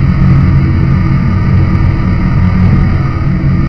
tsm_flareball_float.ogg